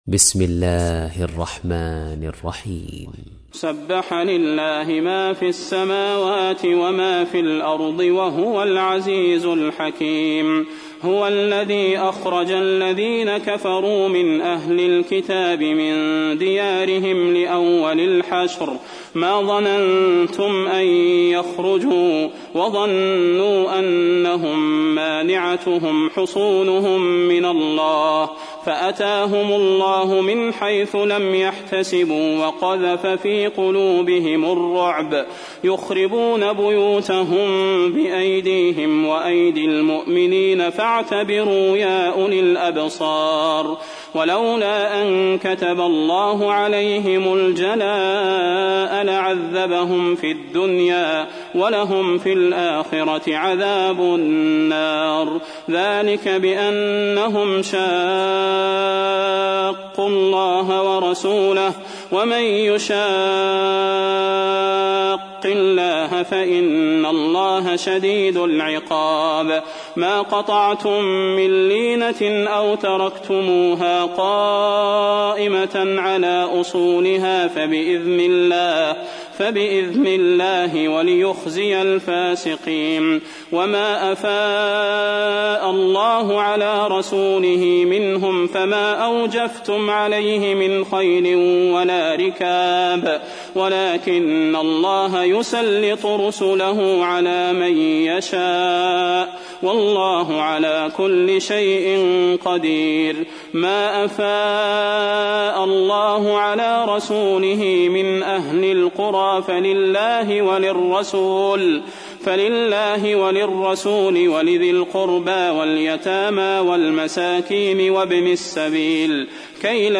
تحميل : 59. سورة الحشر / القارئ صلاح البدير / القرآن الكريم / موقع يا حسين